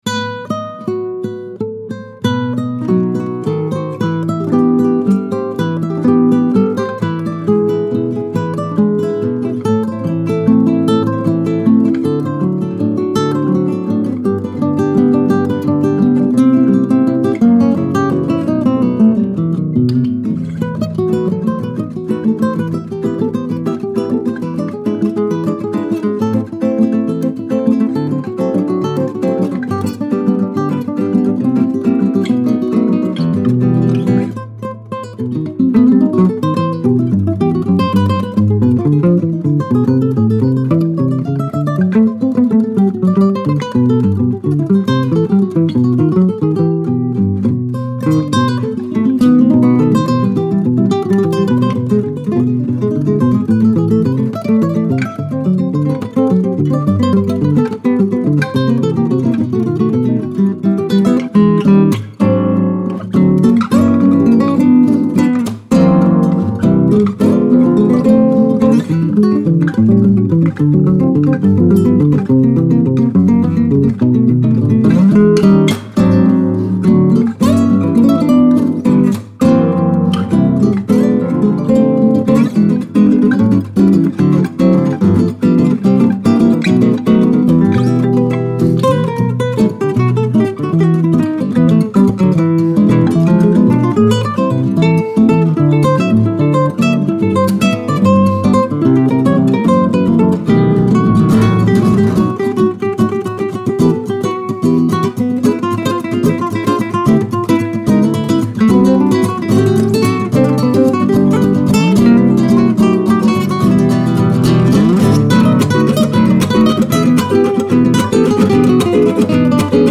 Die Gitarristen